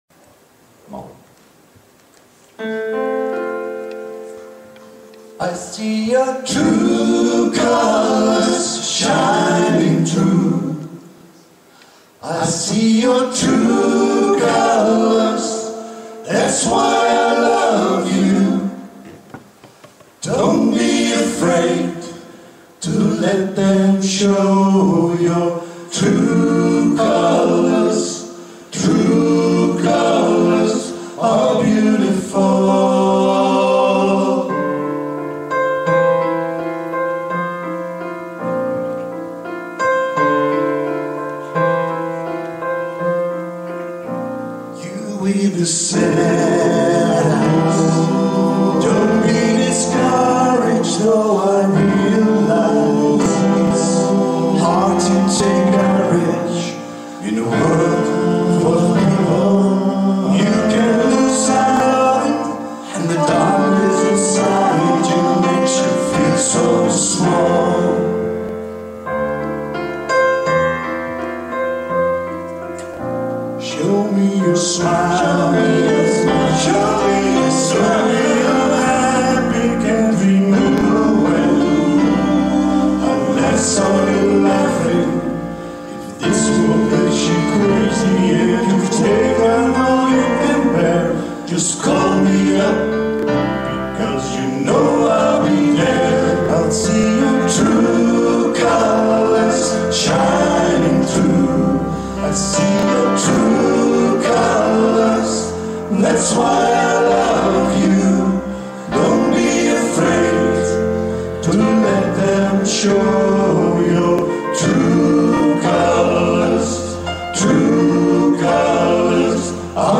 Zanggroep
Vijf mannen.